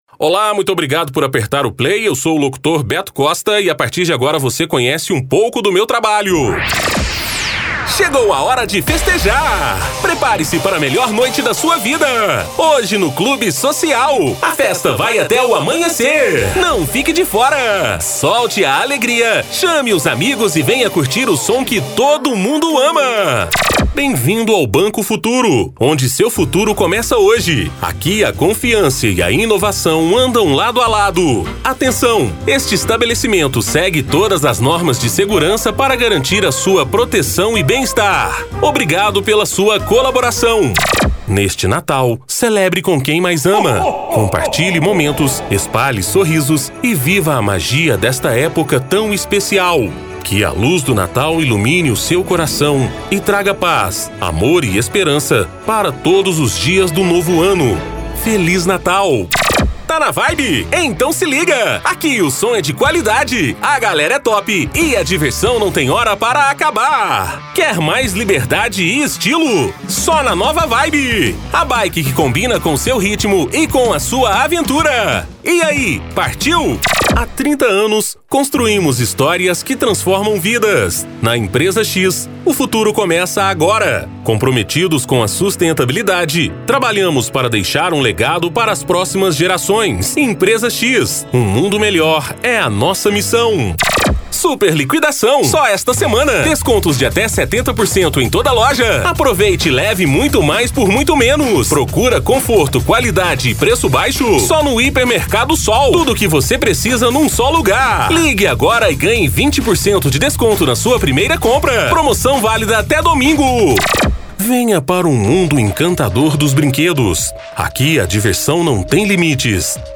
Spot Comercial
Vinhetas
VT Comercial
Animada